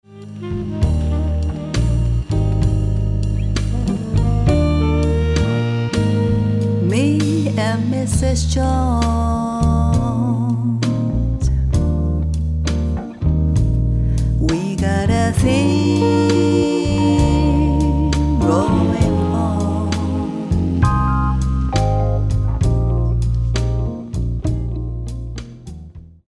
円熟のヴォーカルが描く、ソウル・ジャズ。
Vocal/Chorus
Drums